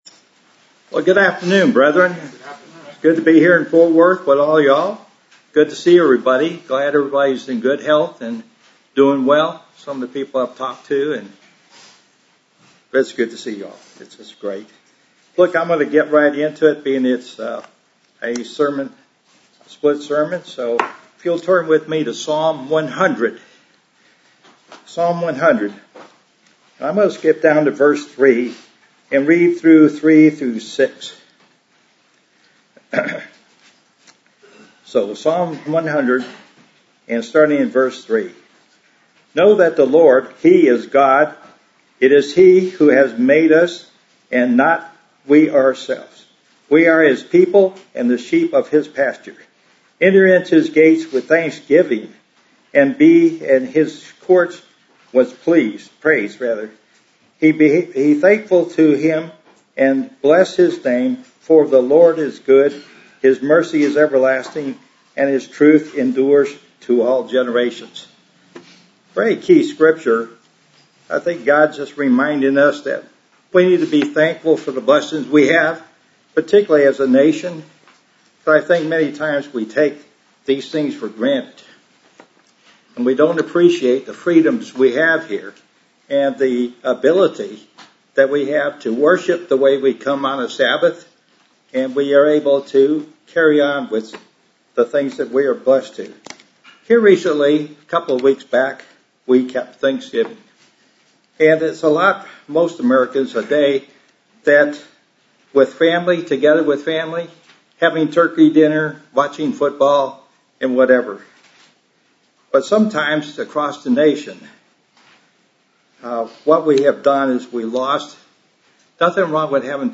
Sermons
Given in Dallas, TX Fort Worth, TX